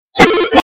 Sound Effects